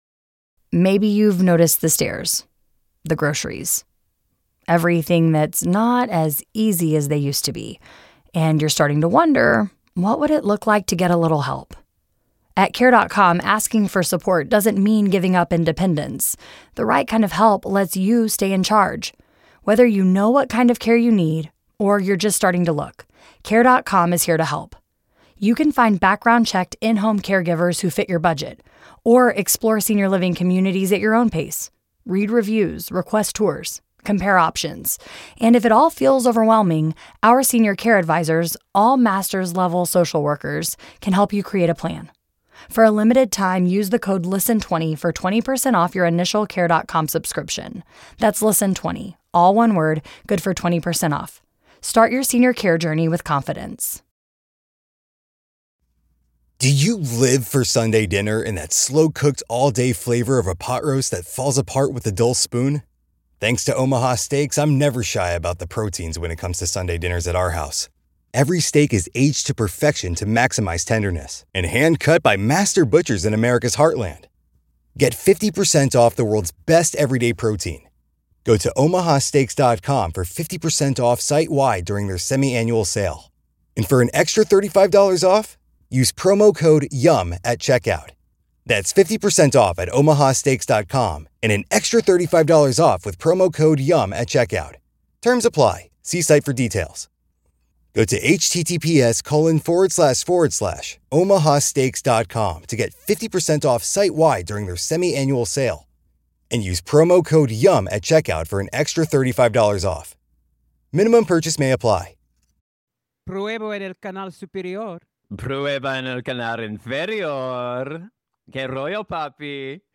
Welcome back to The Kibitz, the show where a pedantic yapper and a bimbo genius chitchat about whatever the hell we want.